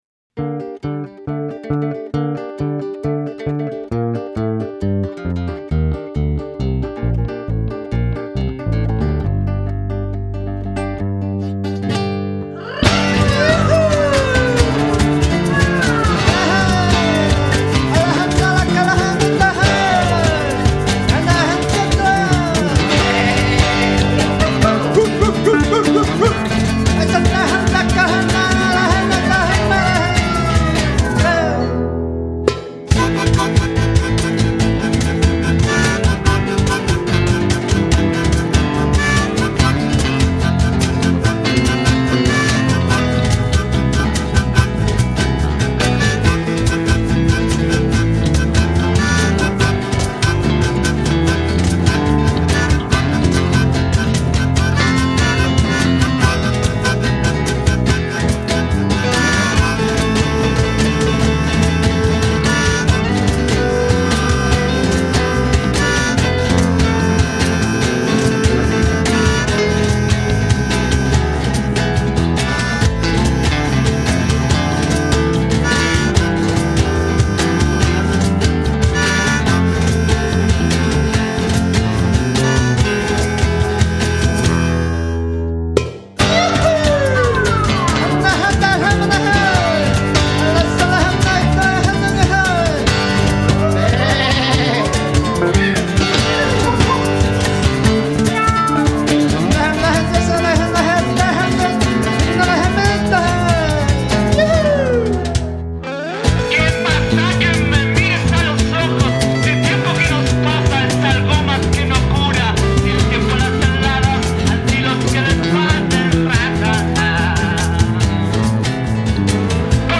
dancehall extreme fast gospel-grunge